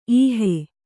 ♪ īhe